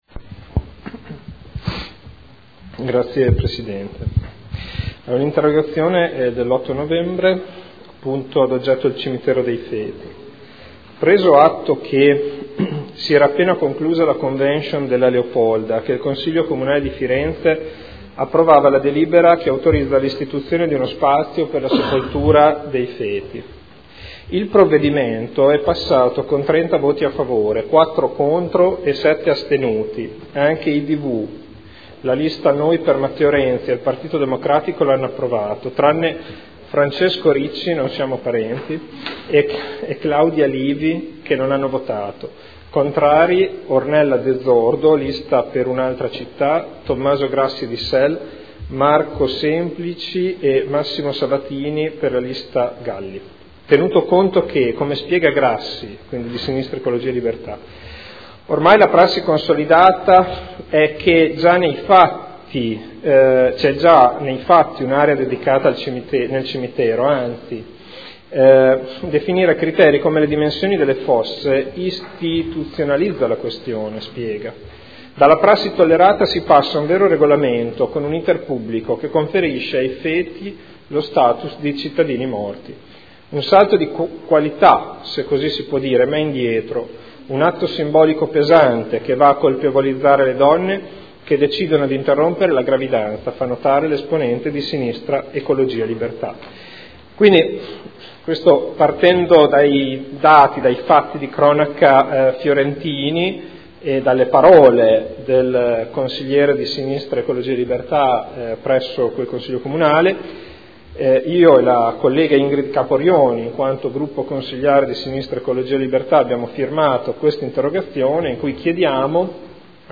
Seduta del 16 gennaio. Interrogazione del gruppo consiliare SEL avente per oggetto: “Il cimitero dei feti” – Primo firmatario consigliere Ricci